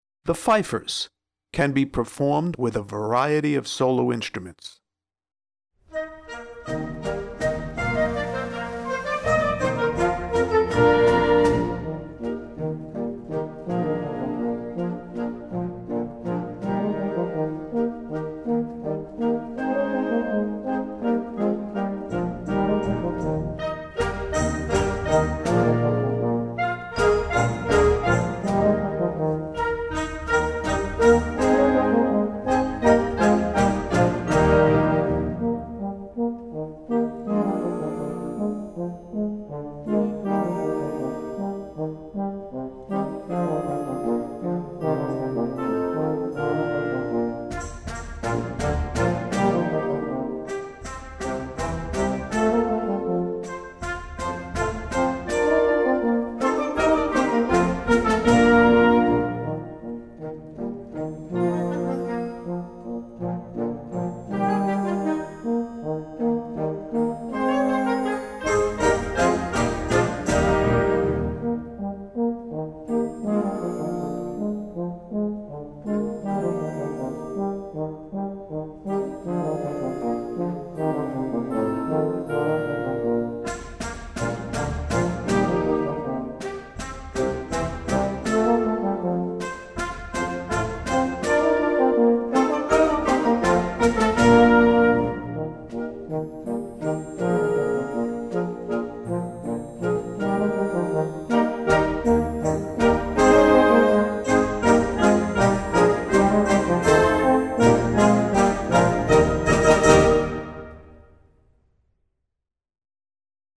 Voicing: Instrument Solo w/ Band